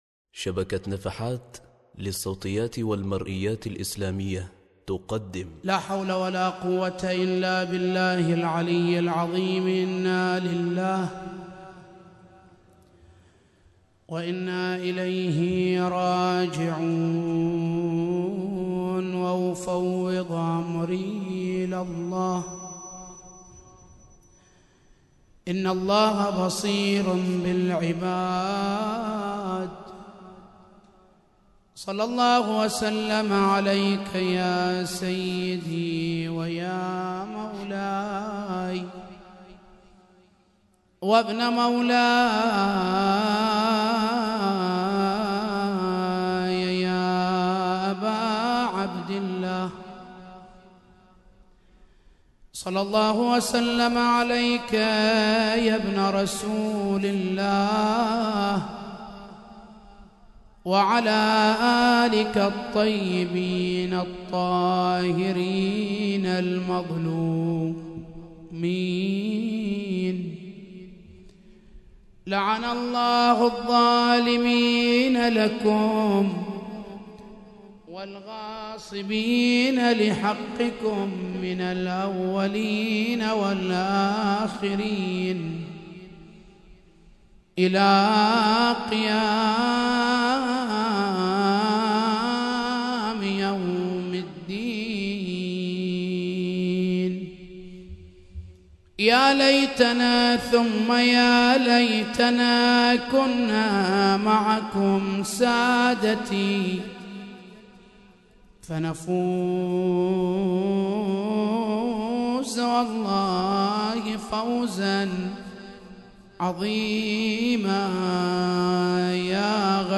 ليلة 8 محرم 1436هـ – عنوان المحاضرة: عوامل الإزدهار لدى الشباب